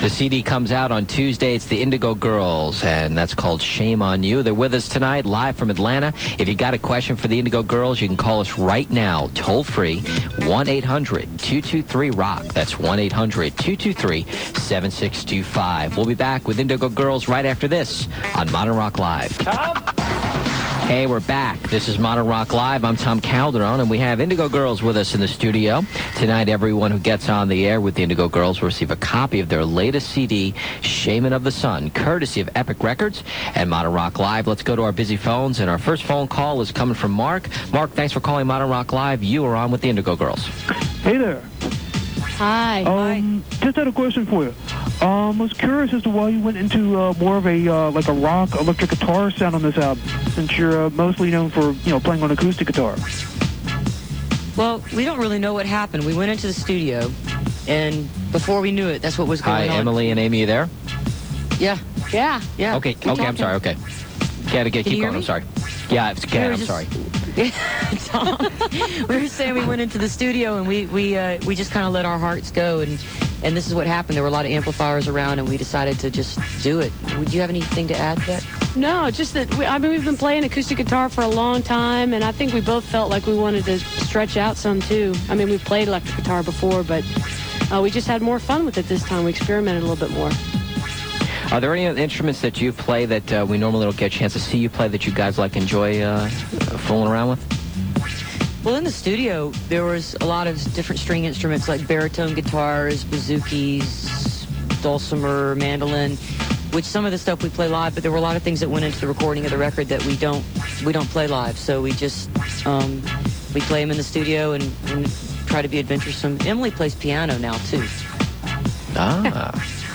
04. interview (4:17)